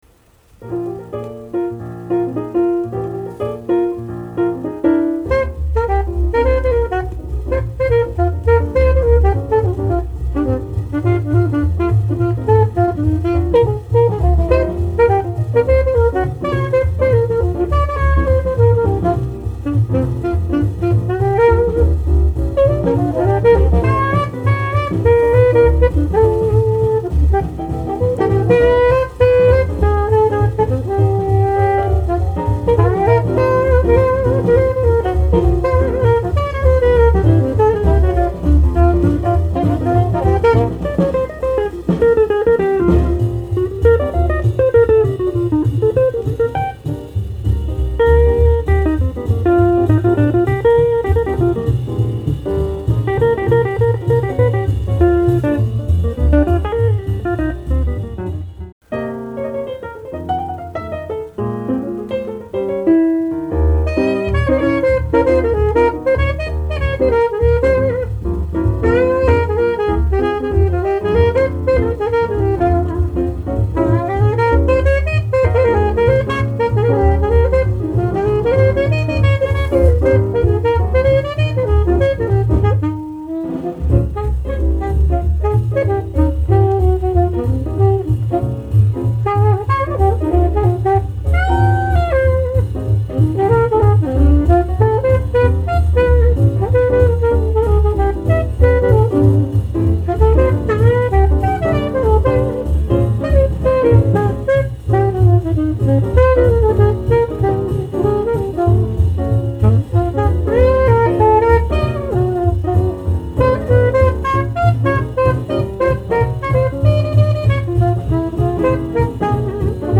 discription:Mono両溝赤ラベル